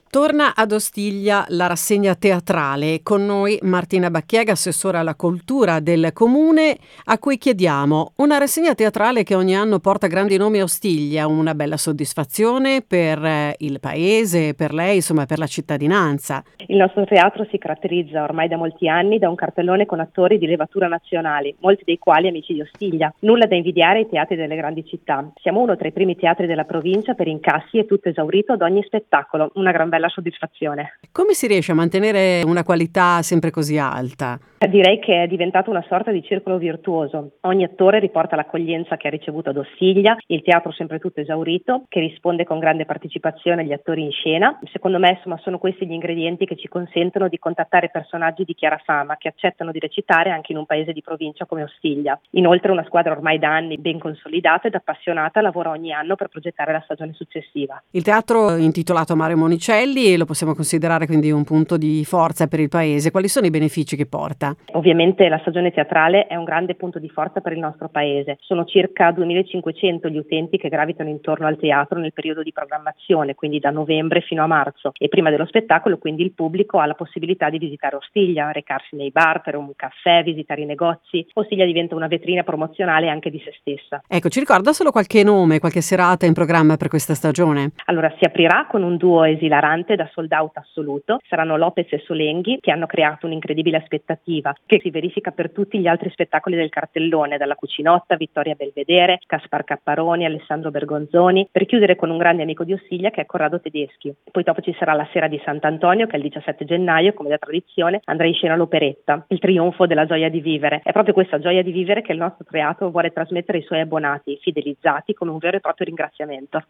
Sarà la coppia Solenghi – Lopez a inaugurare la stagione del Monicelli di Ostiglia, in provincia di Mantova, il 12 novembre: un appuntamento consolidato ormai quello con il calendario del piccolo ma attivissimo teatro, che anche quest’anno vanta nomi di tutto rispetto, attori di fama nazionale, alcuni dei quali tornano anche grazie all’accoglienza ricevuta negli anni passati, come ci ha raccontato l’Assessore alla Cultura Martina Bacchiega: